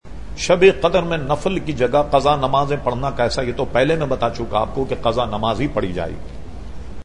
سوال / جواب